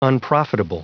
Prononciation du mot unprofitable en anglais (fichier audio)